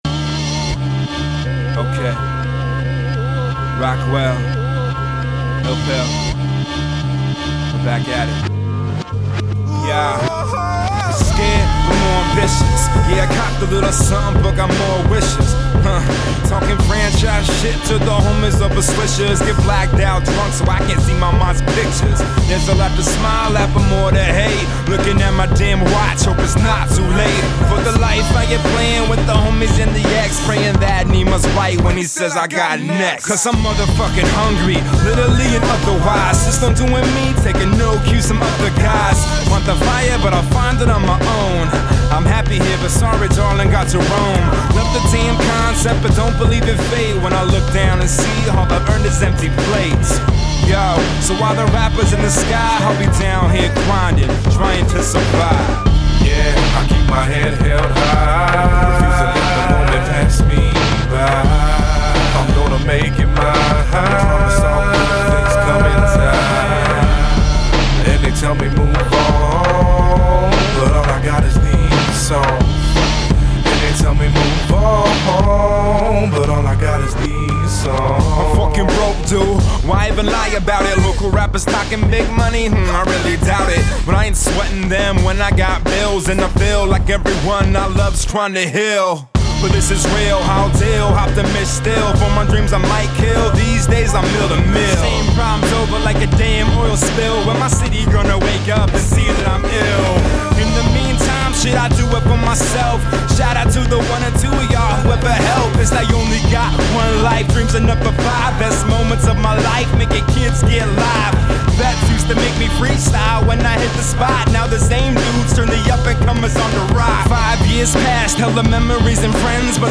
Conscious and positive